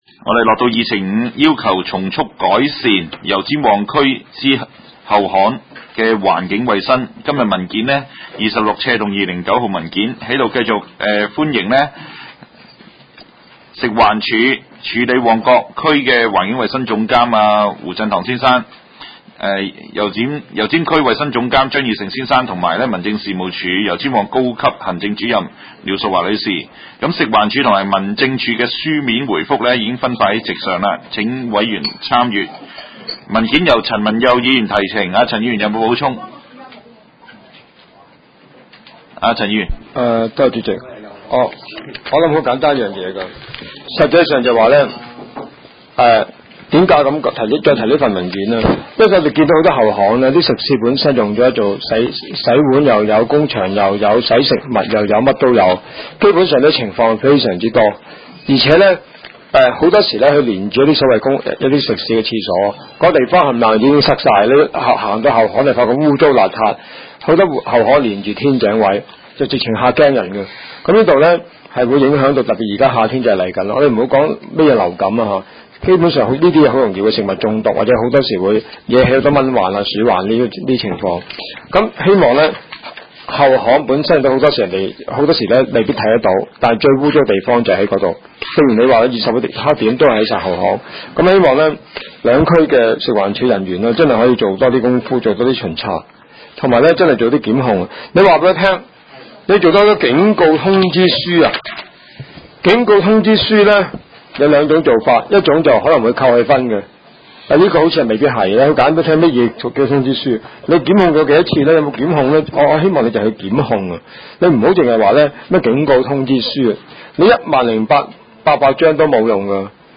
第八次會議
油尖旺區議會會議室